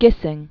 (gĭsĭng), George Robert 1857-1903.